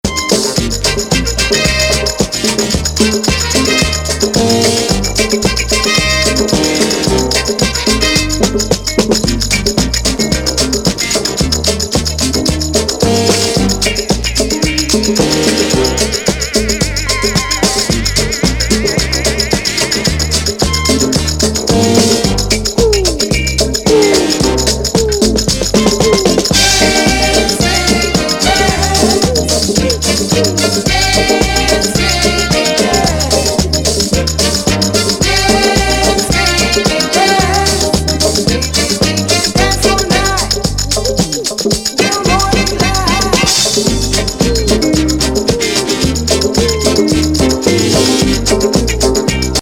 強烈シンセ・スペーシー・ウニウニ・ディスコ・レゲー!!カナダ盤。